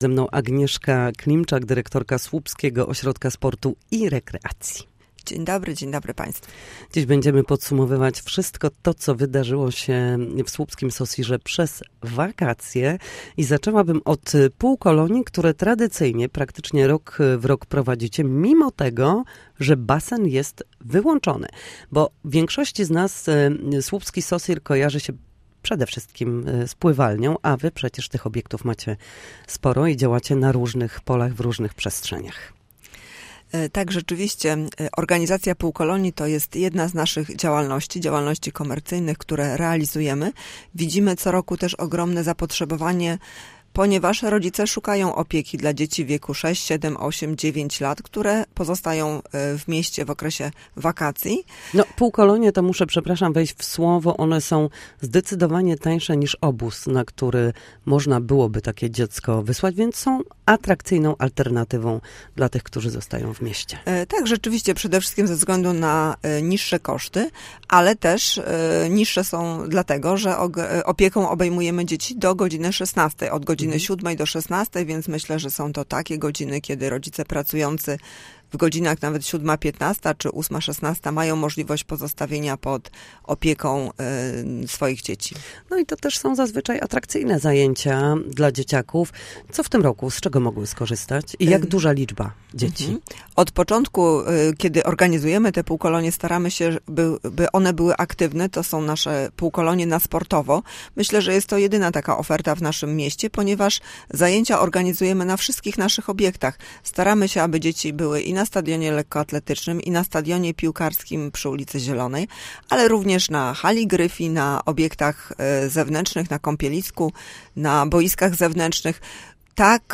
Na naszej antenie podsumowała wakacje, w tym organizowane półkolonie, także dla dzieci z Ukrainy. Mówiła również o inwestycjach, które są przez SOSiR prowadzone.